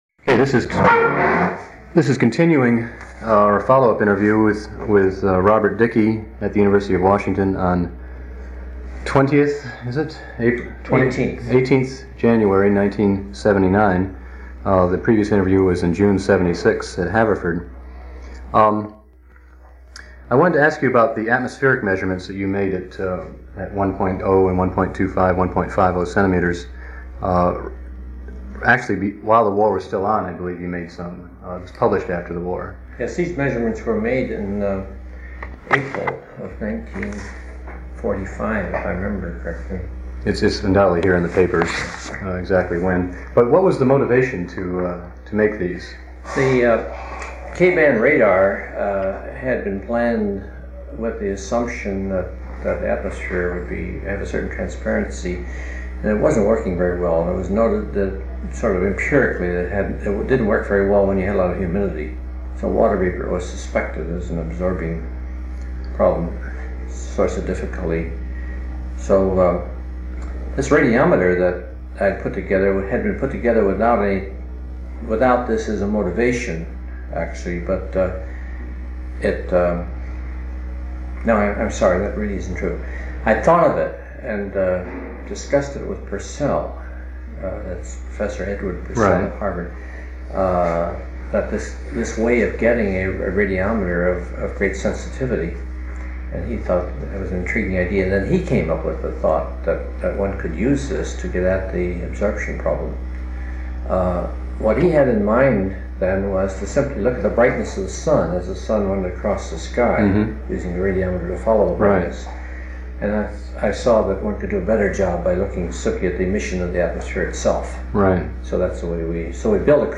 Interview with Robert H. Dicke on 18 January 1979